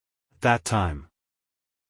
at-that-time-us-male.mp3